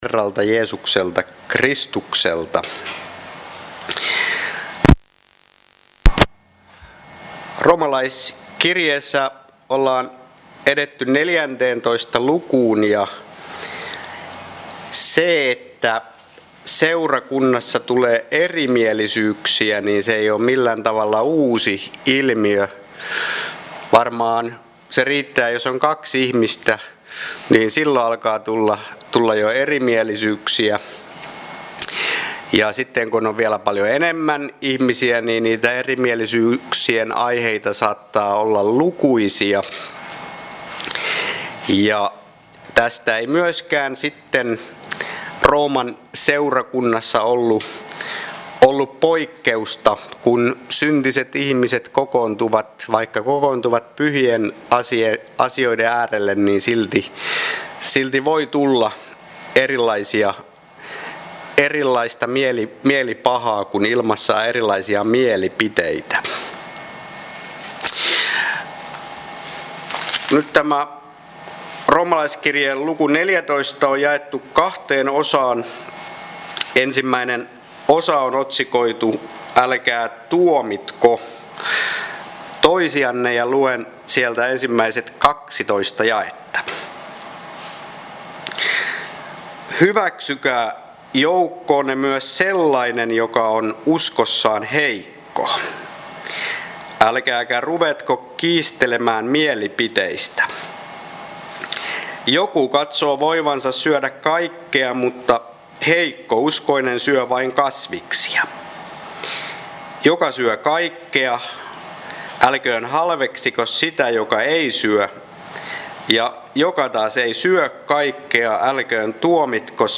Room 14 Luento
Kokoelmat: Seinäjoen Hyvän Paimenen kappelin saarnat